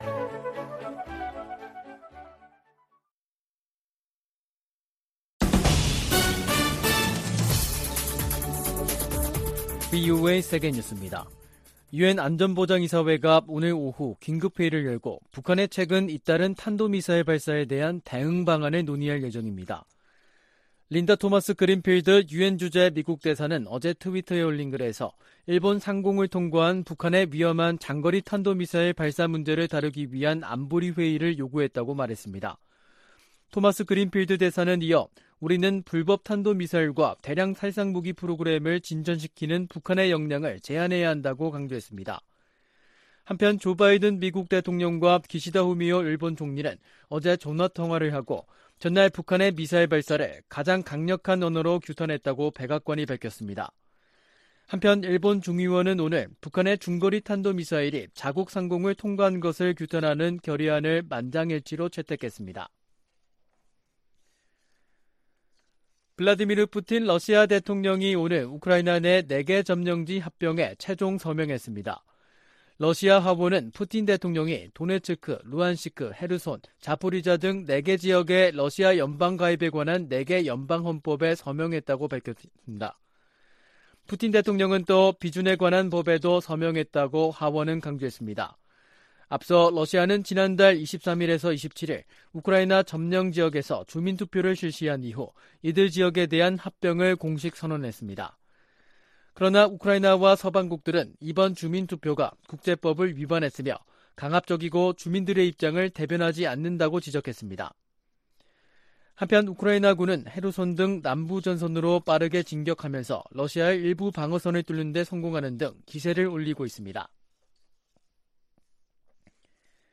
VOA 한국어 간판 뉴스 프로그램 '뉴스 투데이', 2022년 10월 5일 2부 방송입니다. 조 바이든 미국 대통령이 윤석열 한국 대통령에게 친서를 보내 동맹 강화를 강조했습니다. 백악관은 일본 열도를 넘어간 북한의 탄도미사일을 ‘장거리’로 지칭하며 무모한 결정을 강력히 규탄한다고 밝혔습니다. 북한이 탄도미사일을 발사하자 미국과 일본 정상이 전화통화를 하고 긴밀한 협력 의지를 재확인했습니다.